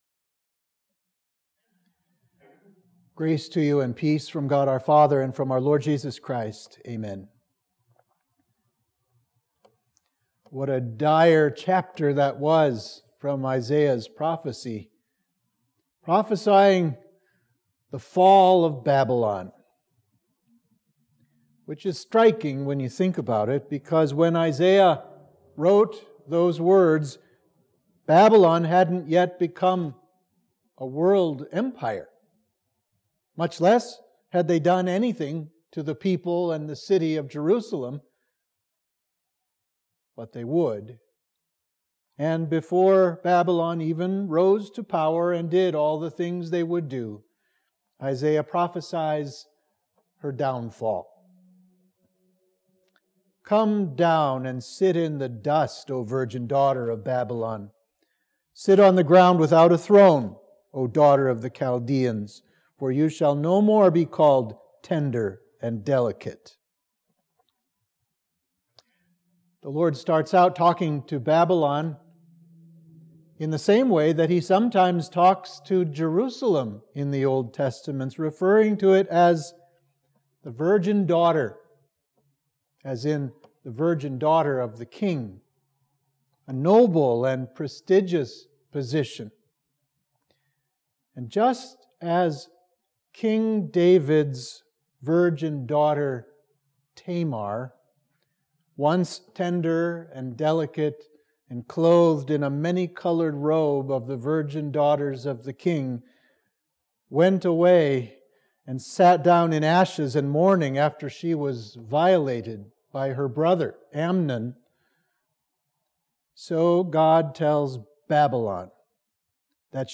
Sermon for Midweek of Easter 6